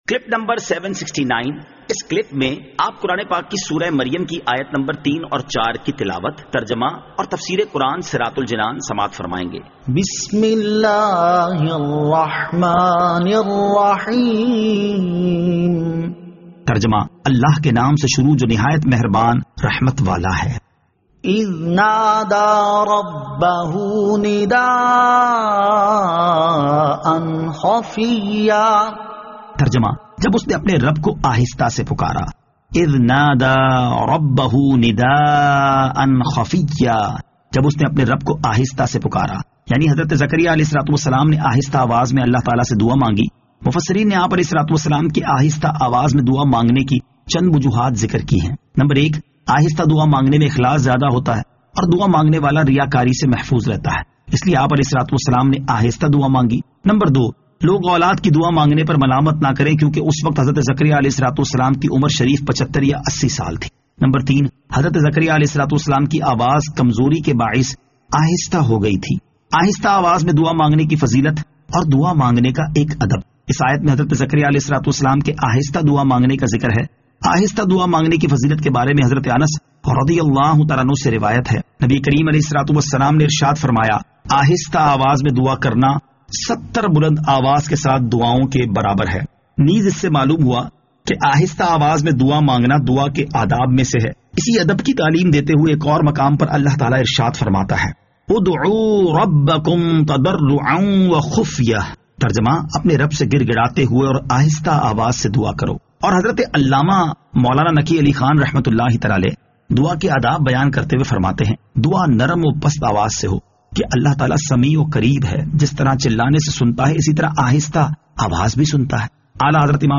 Surah Maryam Ayat 03 To 04 Tilawat , Tarjama , Tafseer